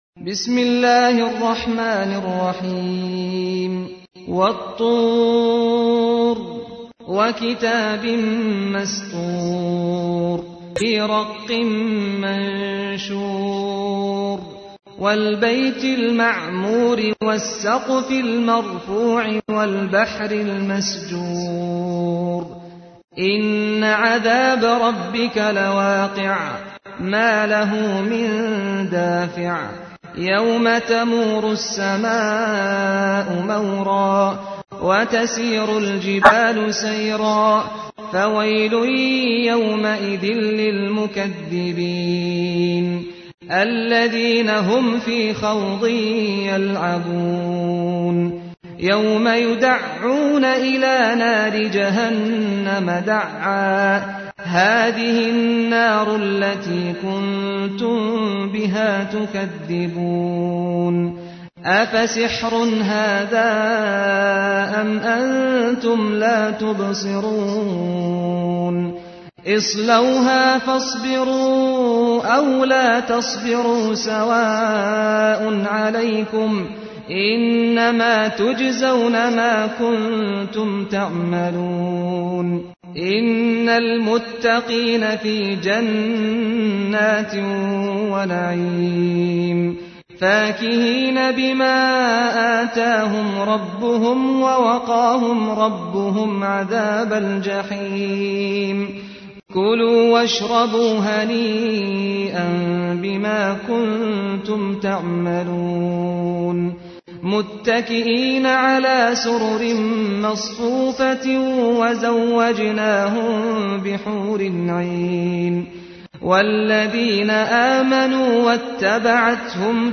تحميل : 52. سورة الطور / القارئ سعد الغامدي / القرآن الكريم / موقع يا حسين